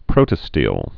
(prōtə-stēl, prōtə-stēlē)